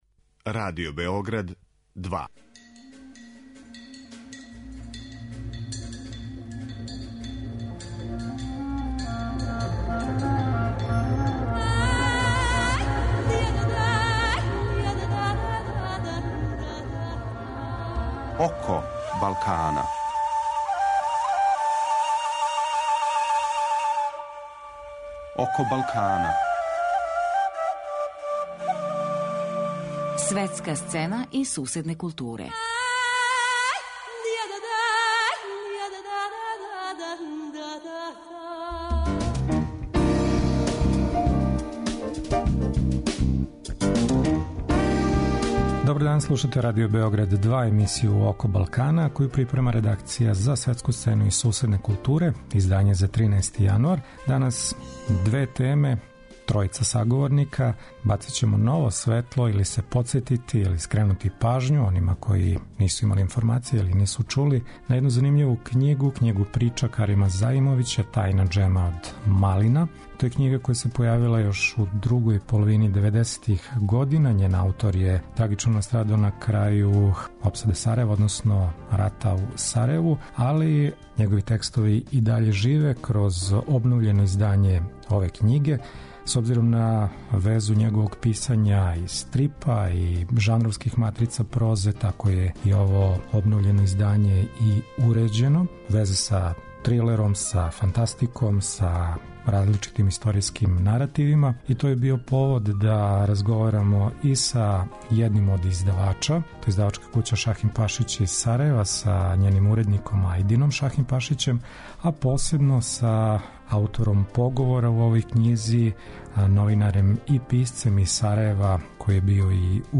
Други саговорник нам је